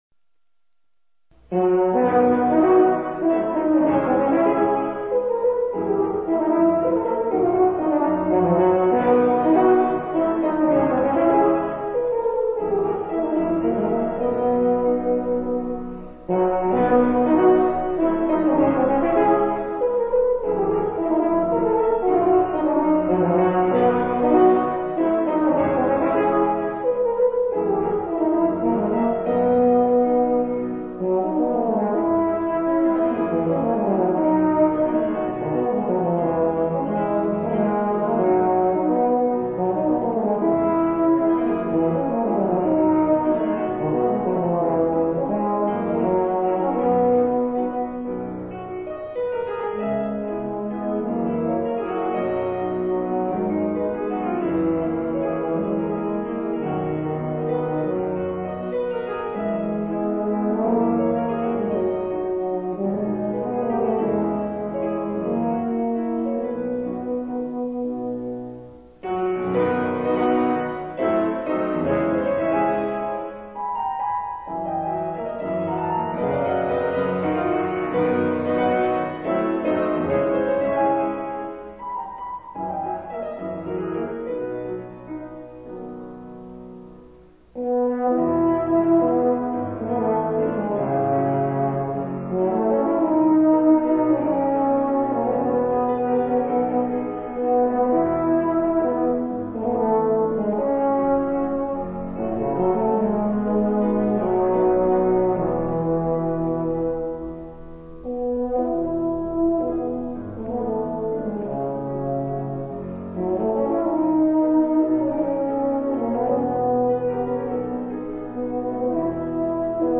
For Euphonium Solo
Euph, Clar, Tpt or Tbn with Piano.